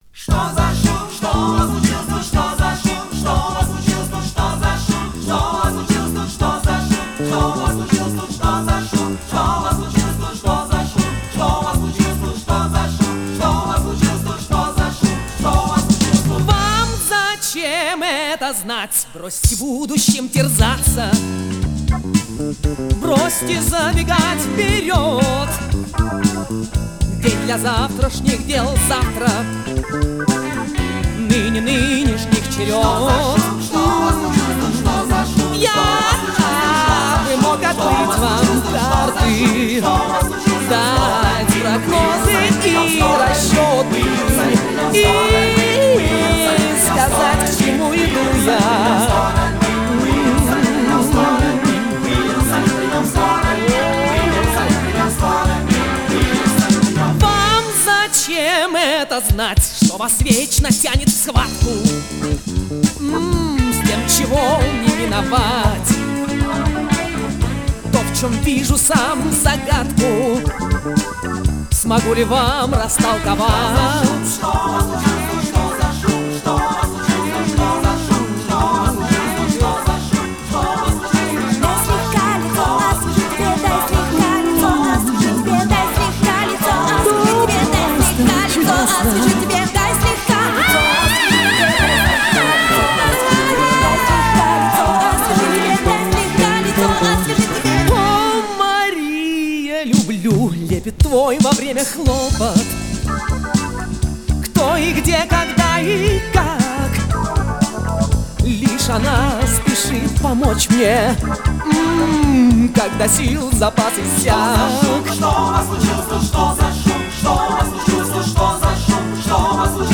Жанр: Рок-опера
Формат: Vinil, 2 x LP, Stereo, Album
Стиль: Вокал